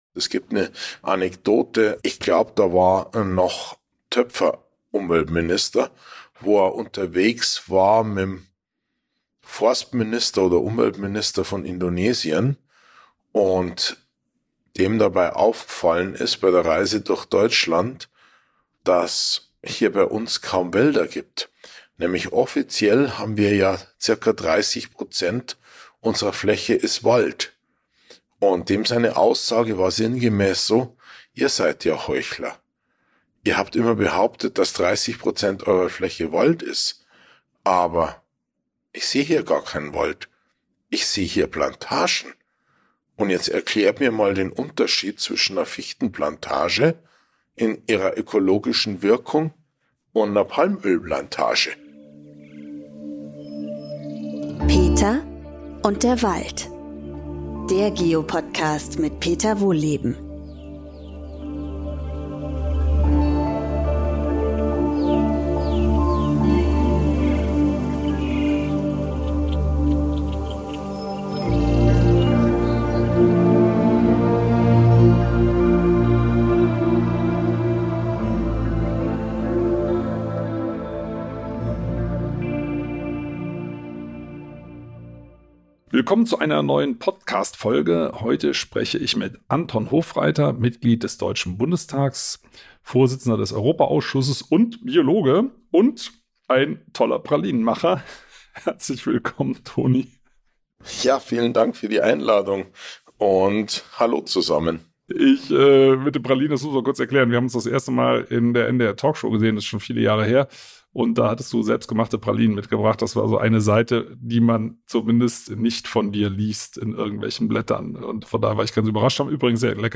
Diesmal trifft Peter Wohlleben auf Dr. Anton Hofreiter, der Mitglied des Bundestages und Biologe ist.